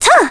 Ophelia-vox-Landing.wav